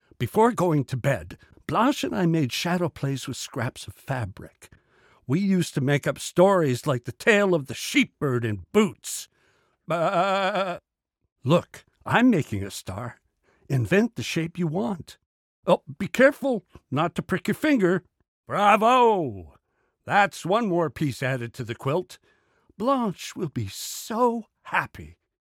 Narration - ANG